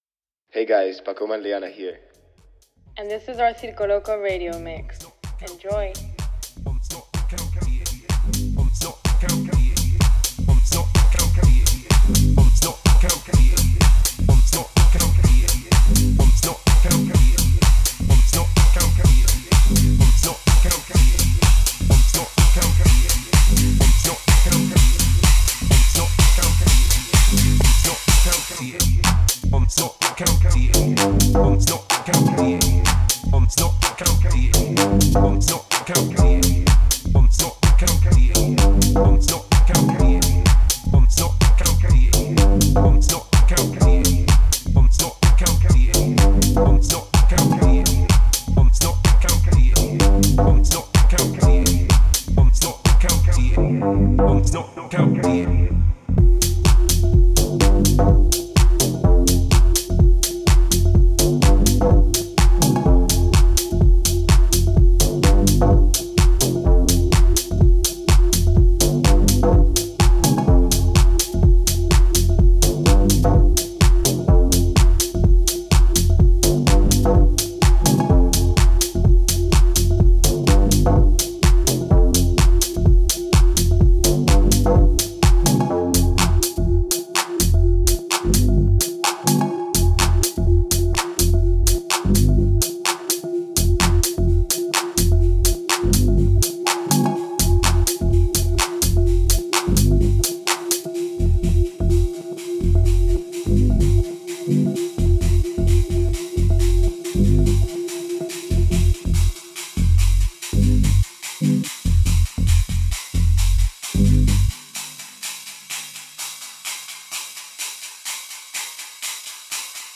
Bringing you new mixes from the best DJs in the world.